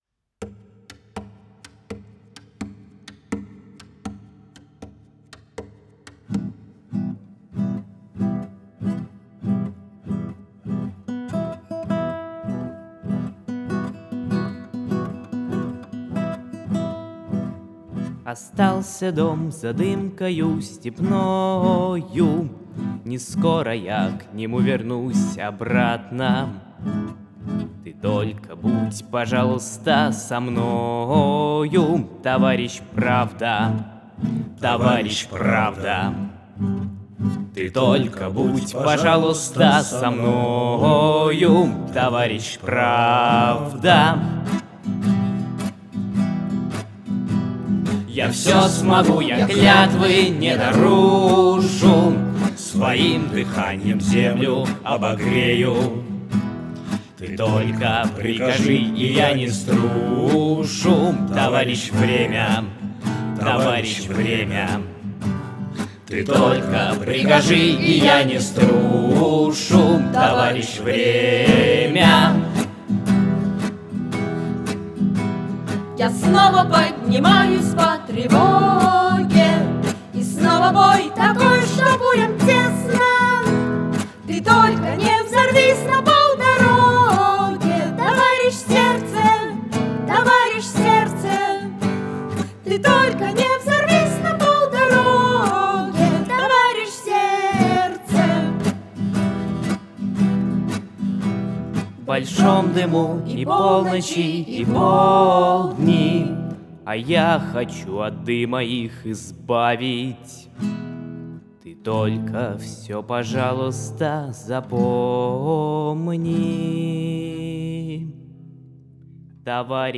Аудио Детско-юношеского центра Рэй-Спорт "ТОВАРИЩ ПЕСНЯ" Исполняет вокально-инструментальный ансамбль "РЭЙ-ком".